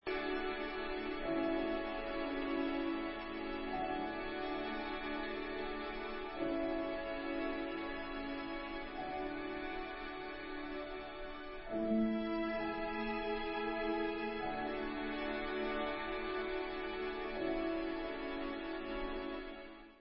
Sostenuto tranquillo ma cantabile
sledovat novinky v kategorii Vážná hudba